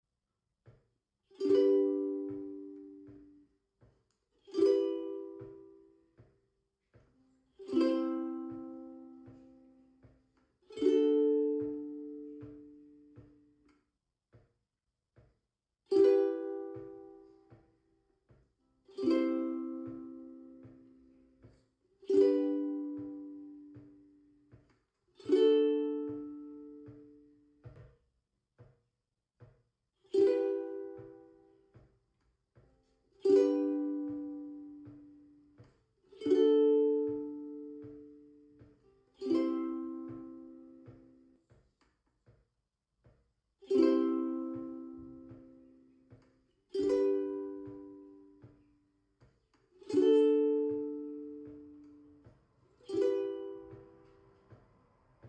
🎸 4 E Minor Chord sound effects free download
🎸 4 E Minor Chord Progressions for Ukulele Moody, emotional & easy to play 🎶